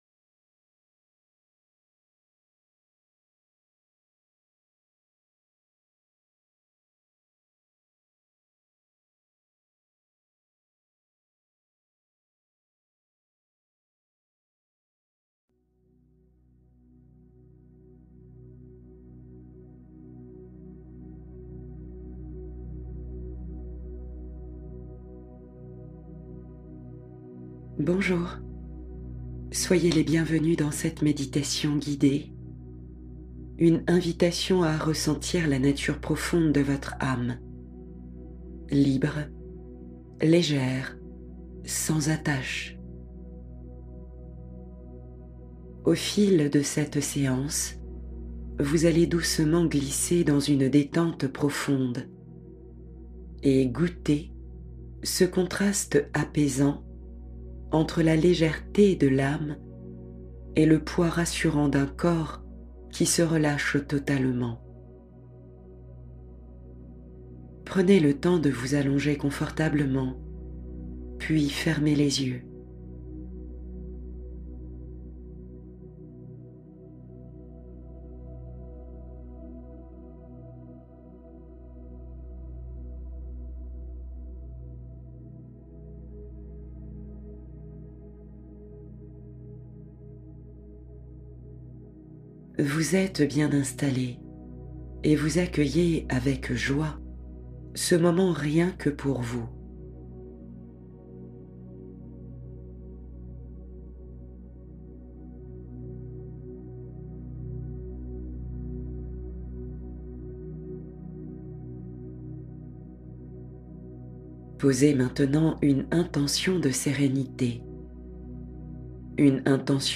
Se sentir bien intérieurement — Relaxation guidée pour plus de confort